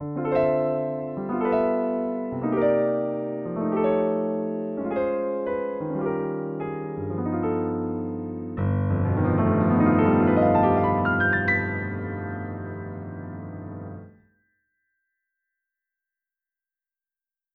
Performance seems good, but it sounds muffled compared to my normal PC.
The Pi file is not only "duller", but also much quieter.
And the difference in volume and brightness is obvious.